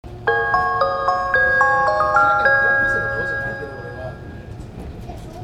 駅発車後用と到着前用のオリジナルがあり、到着前用は京阪のチャイムに似ています。“
駅発車後用チャイム
nishitetsu-chime1.mp3